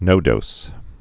(nōdōs)